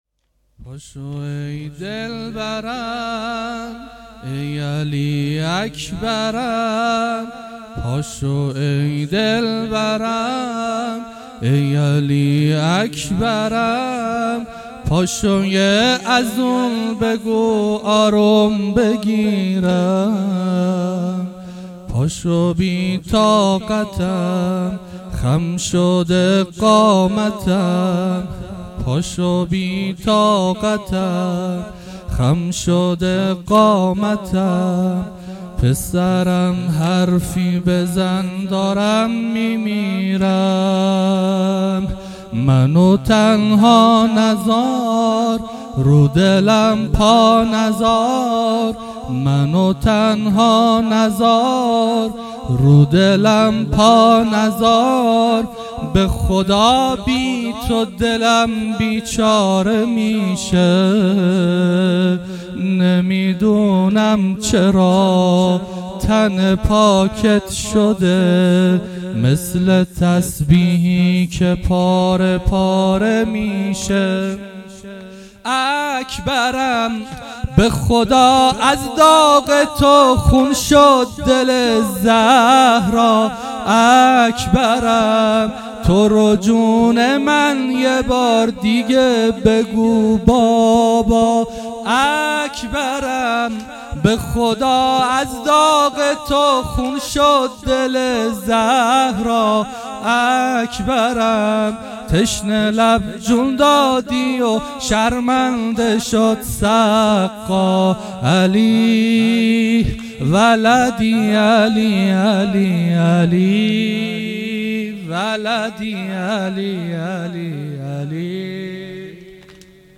مراسم عزاداری دهه دوم محرم الحرام 1399 - گلزار شهدای هرمزآباد